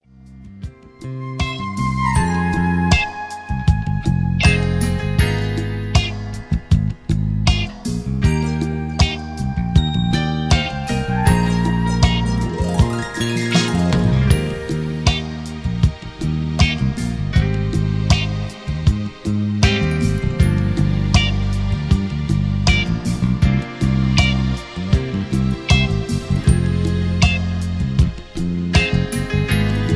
Key-Eb) Karaoke MP3 Backing Tracks
Just Plain & Simply "GREAT MUSIC" (No Lyrics).